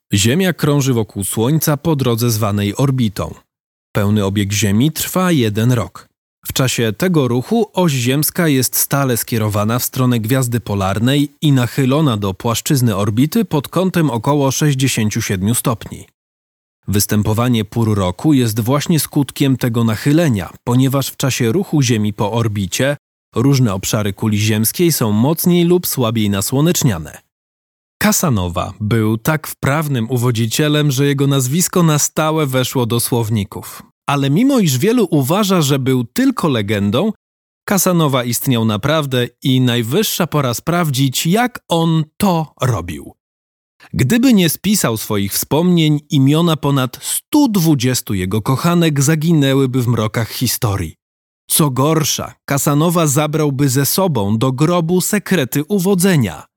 Male 30-50 lat
Voice artist and radio DJ, half a lifetime behind the microphone with a voice older than his age suggests.
Nagranie lektorskie